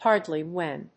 アクセントhárdly…when [befòre]…